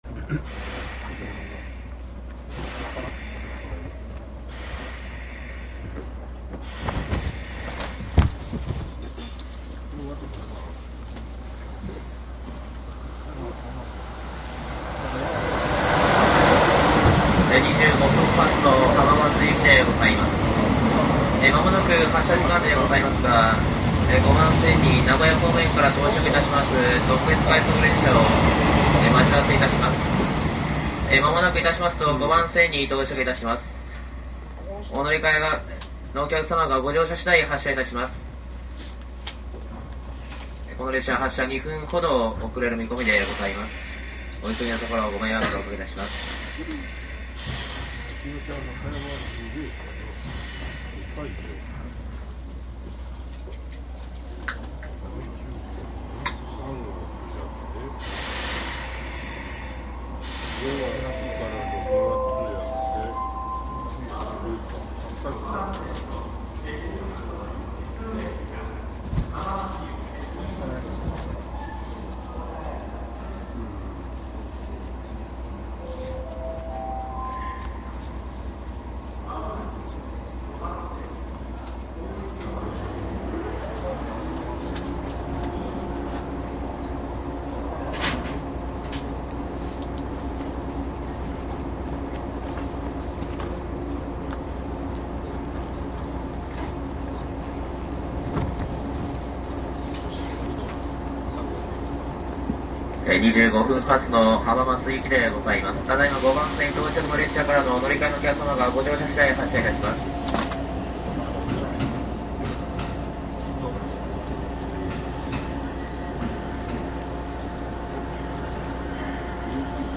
東海 313系 新快速走行音 ＣＤ♪
東海道線上り 313系 新快速 録音 ＣＤです。
■【新快速】名古屋→豊橋 クモハ313－6
マスター音源はデジタル44.1kHz16ビット（マイクＥＣＭ959）で、これを編集ソフトでＣＤに焼いたものです。